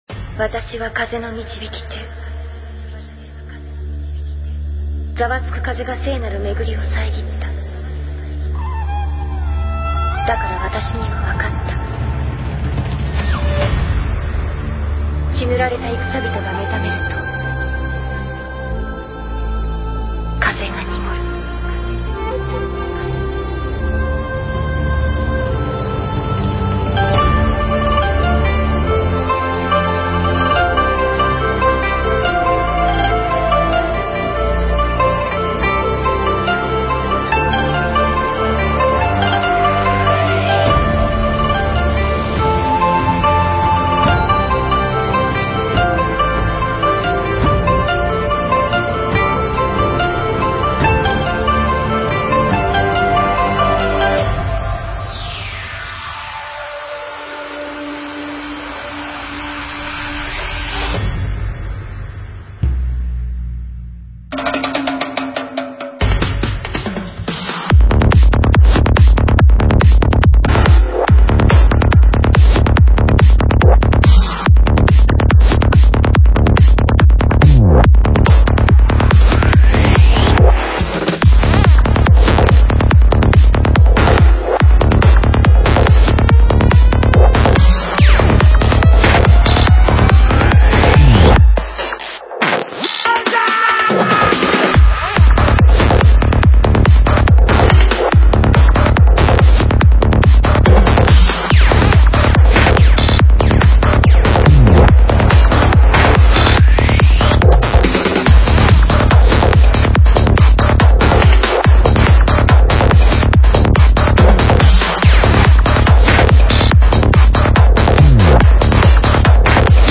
Файл в обменнике2 Myзыкa->Psy-trance, Full-on
Стиль: Trance / Psy Trance